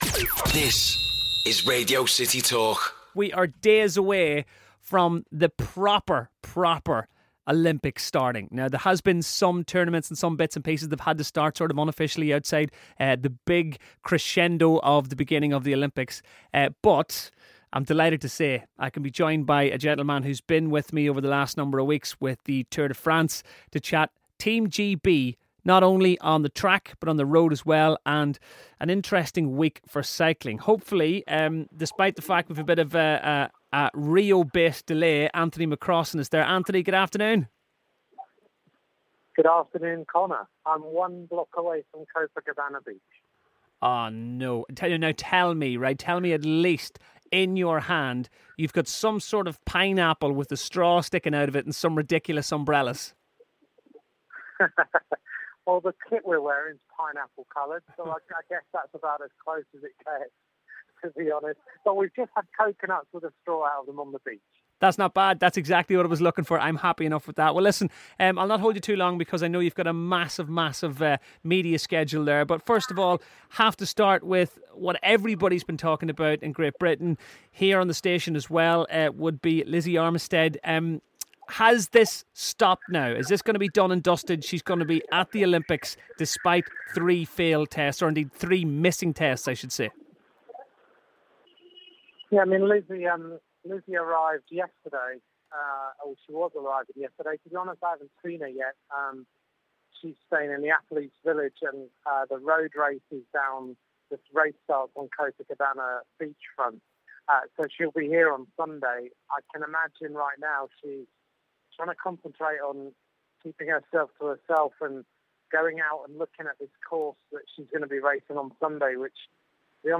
Cycling expert